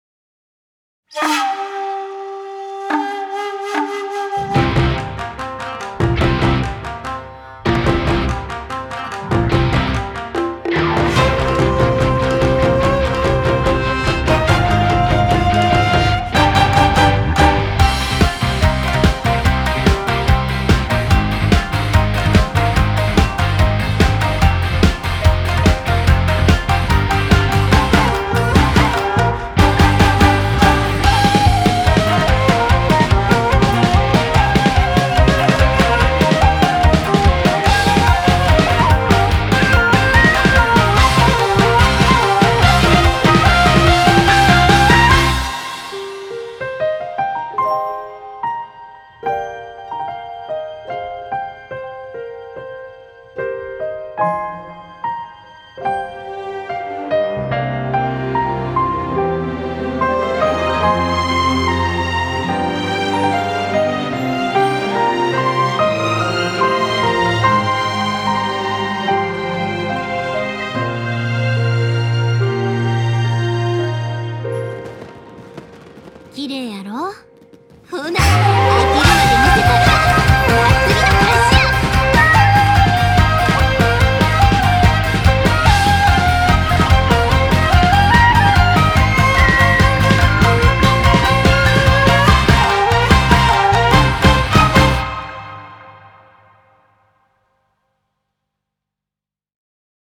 BPM144--1
Audio QualityPerfect (High Quality)